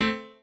piano4_7.ogg